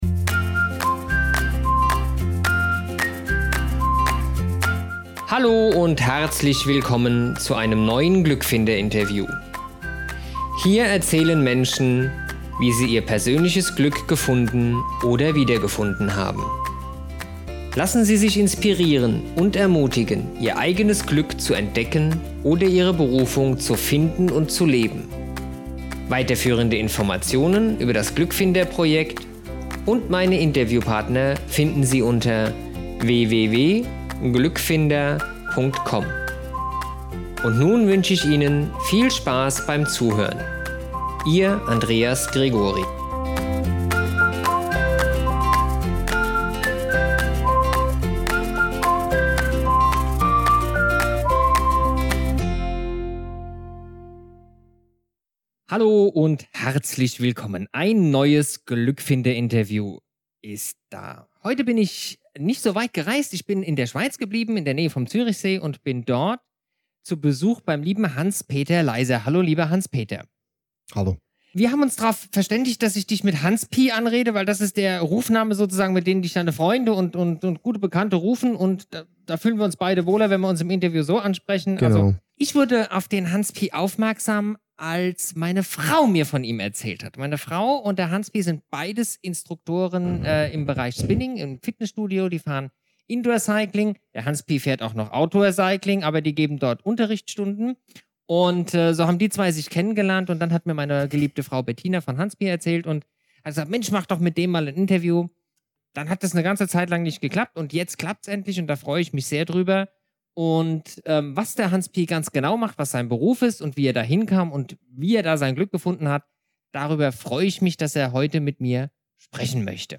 Zuhören macht glücklich - Ein Gefängnisseelsorger erzählt über das Glück